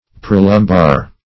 Search Result for " prelumbar" : The Collaborative International Dictionary of English v.0.48: Prelumbar \Pre*lum"bar\, a. (Anat.)